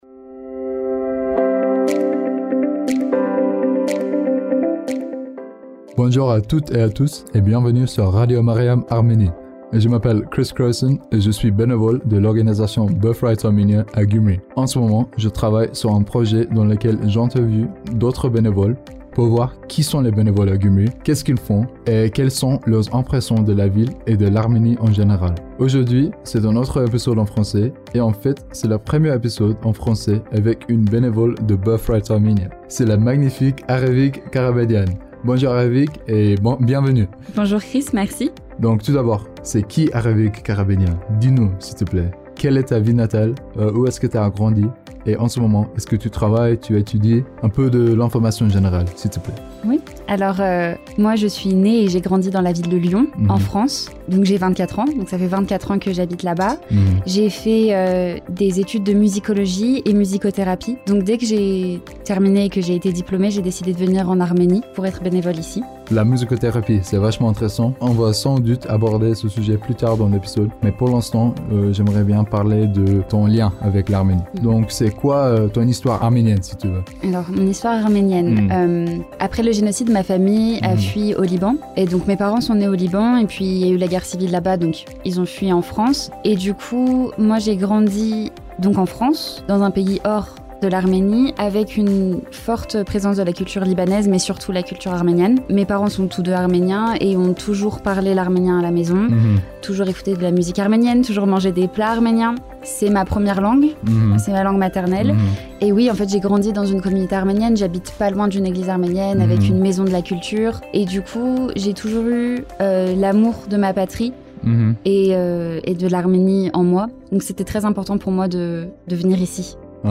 Les interviews avec les bénévoles de Birthright Armenia à Gyumri